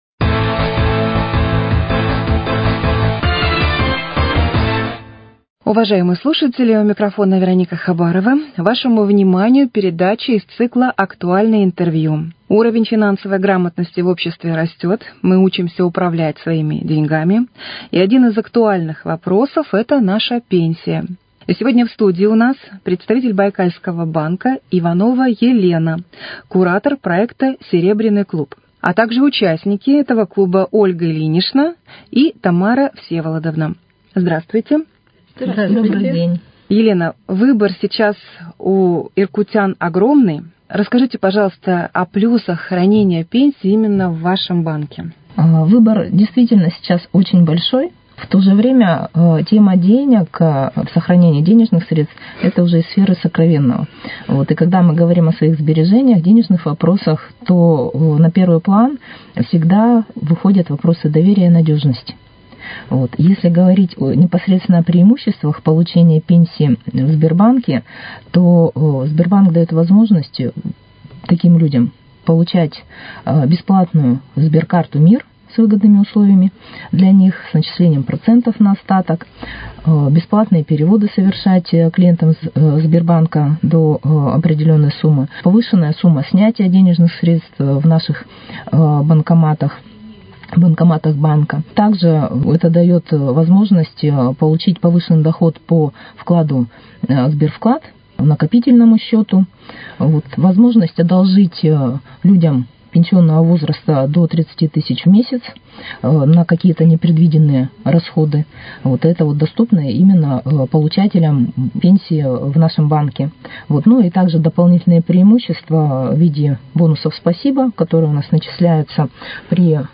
Актуальное интервью: О работе проекта "Серебряный клуб"